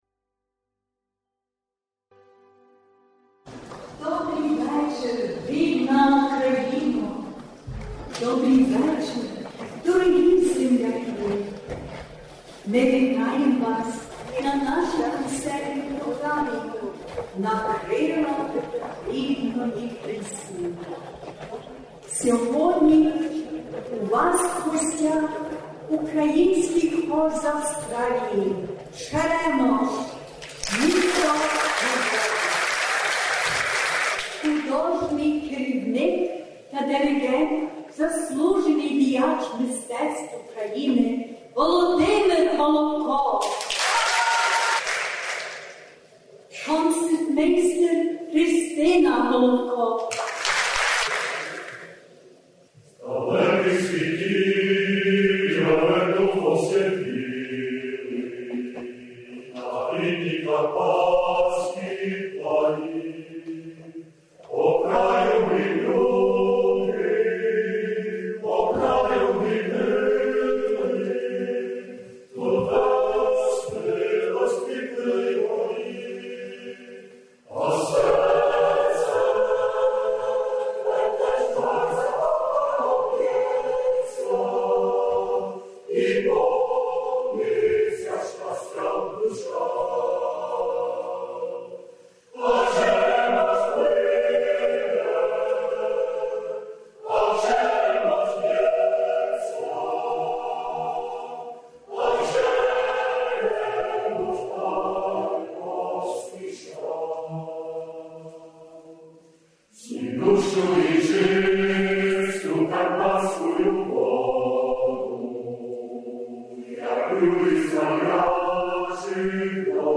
Минає 75-ліття СУМу поза Україною і 70-ліття з часу народження мельбурнських сумівців. У цій історії досить вагоме й своєрідне місце займає сумівський хор "Черемош", який уже 46 років береже й пропагує українські пісні й музику у багатонаціональній Австралії. Нині частково заглянемо в історію "Черемоша", зокрема, його славну мистецьку подорож Україною, яку більшість австралійських українців-хористів відвідали уперше.